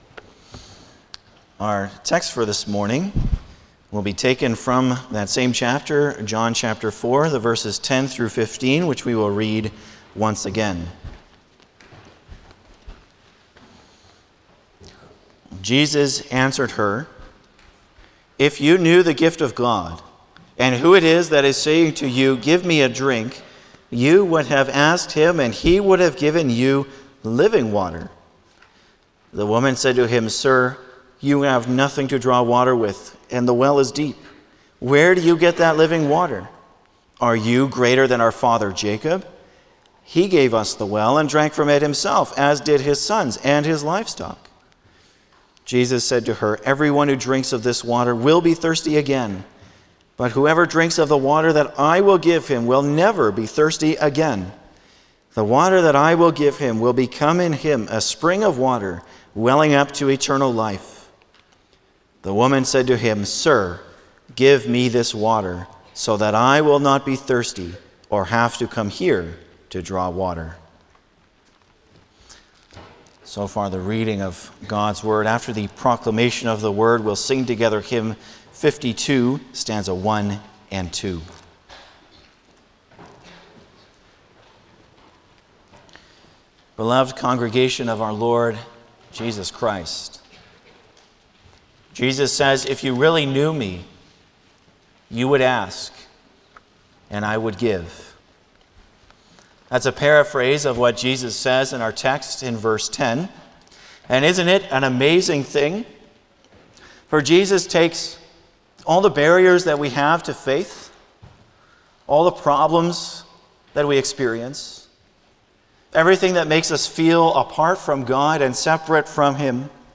Passage: John 4:10-15 Service Type: Sunday morning
05-Sermon.mp3